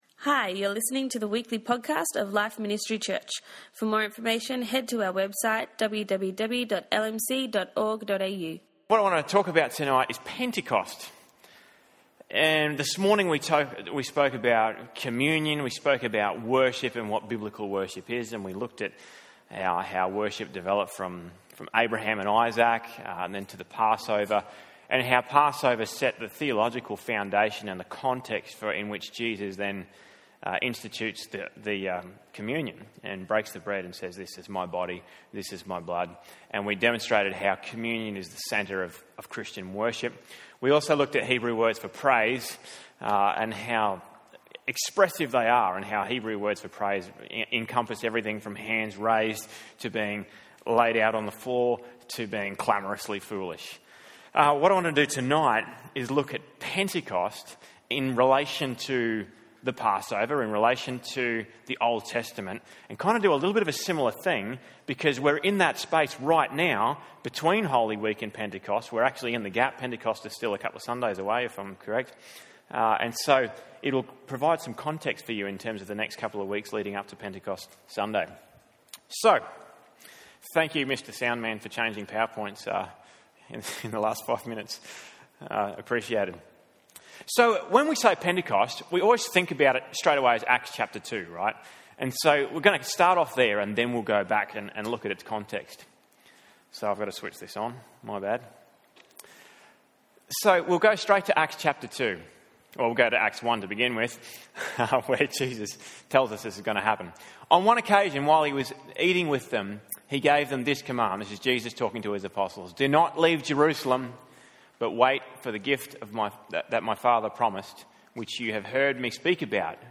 Sunday night message